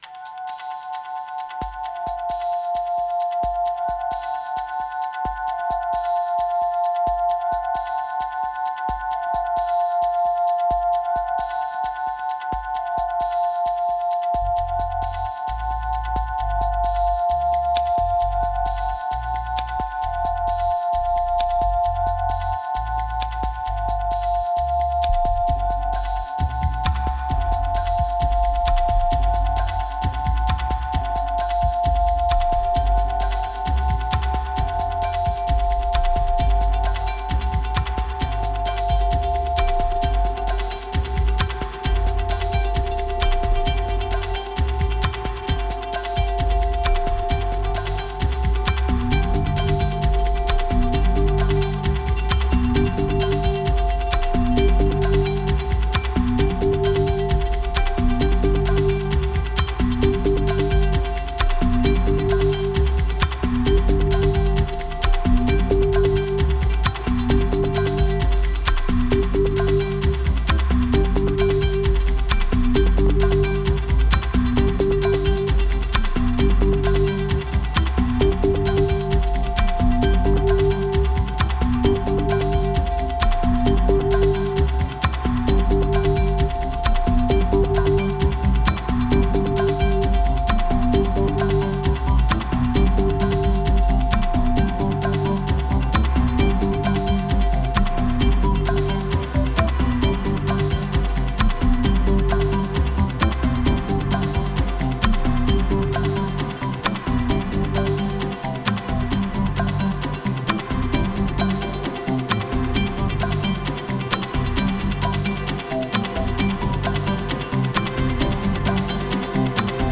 A collection of ambient beat tracks.
866📈 - 80%🤔 - 66BPM🔊 - 2025-03-22📅 - 266🌟
Sampled from an absurd 80s band.